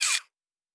pgs/Assets/Audio/Sci-Fi Sounds/Mechanical/Servo Small 8_1.wav at master
Servo Small 8_1.wav